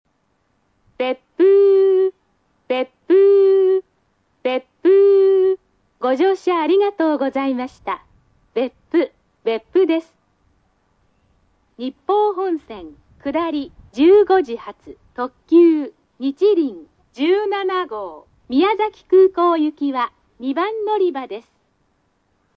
駅の音
スピーカー：ソノコラム
音質：Ｂ
接近放送（普通・杵築）　(116KB/23秒)